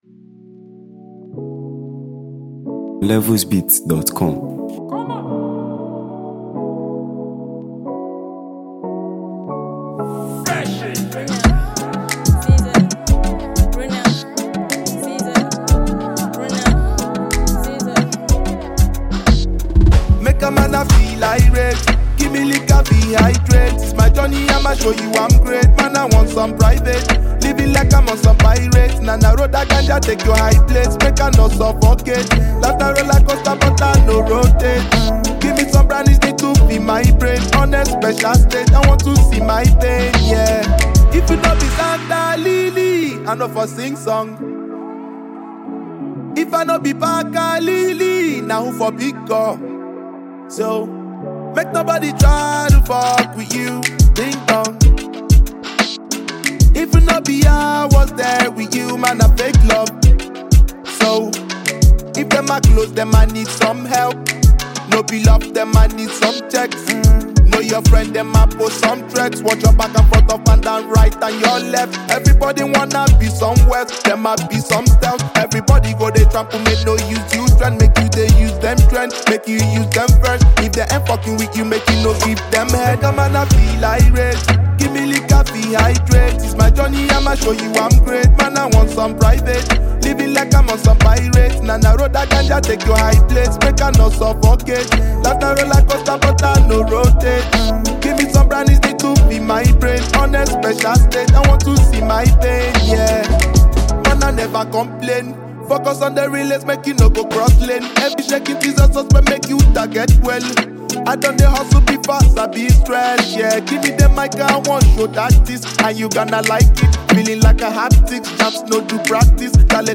vibrant and uplifting single